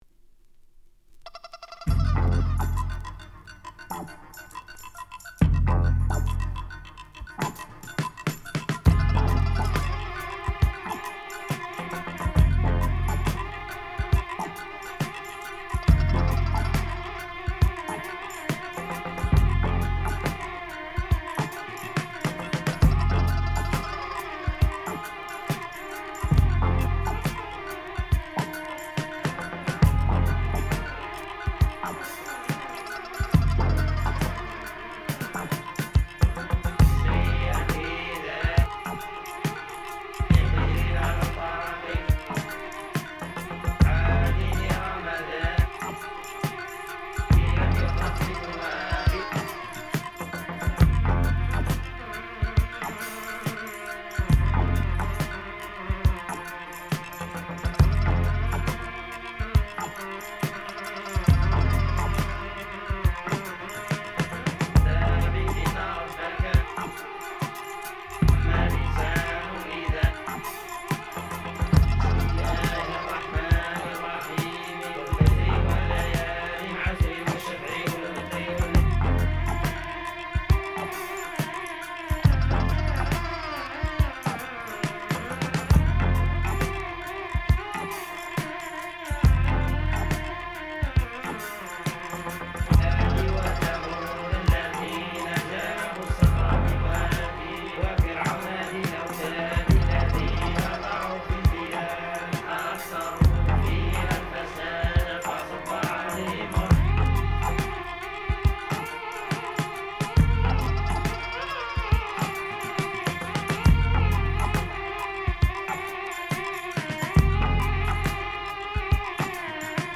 Genre: Experimental.